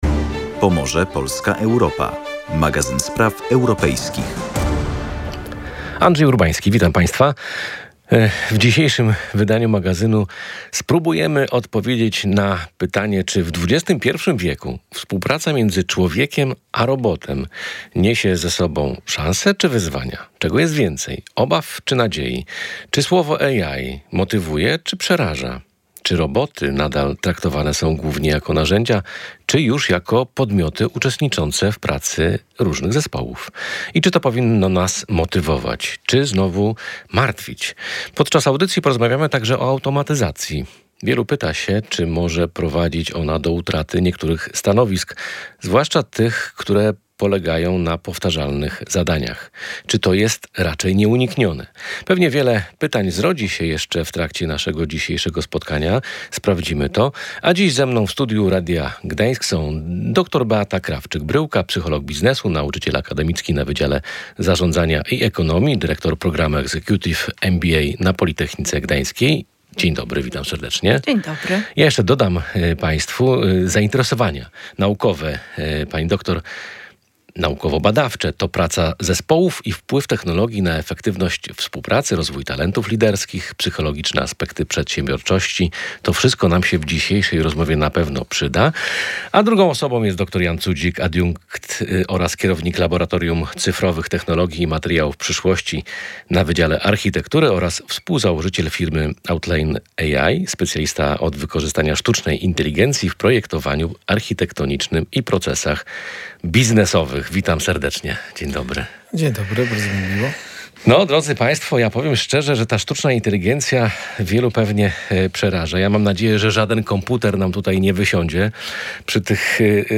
Sztuczna inteligencja w służbie człowiekowi, czy odwrotnie? Dyskusja ekspertów